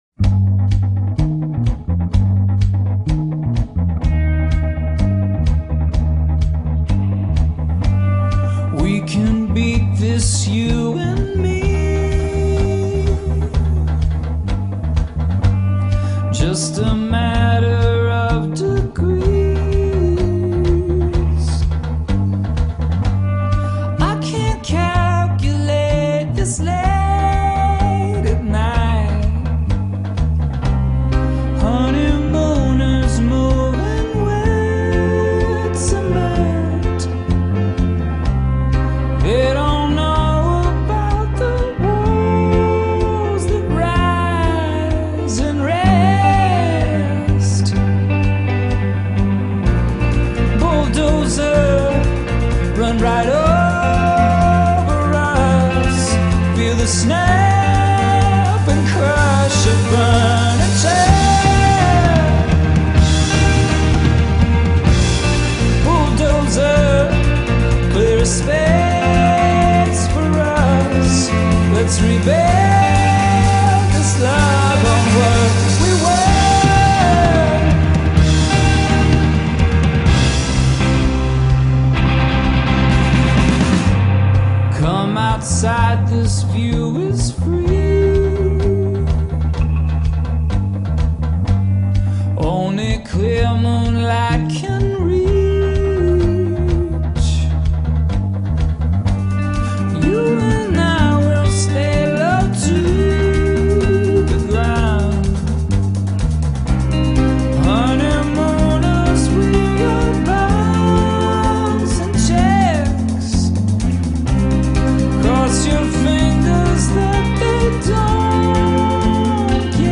Beautiful destruction.